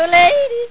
Want to hear a jodel ?
jodel.wav